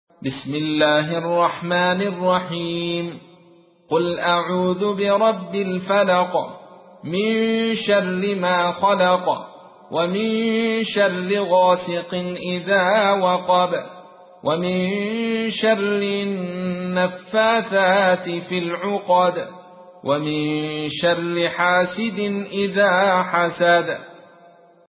تحميل : 113. سورة الفلق / القارئ عبد الرشيد صوفي / القرآن الكريم / موقع يا حسين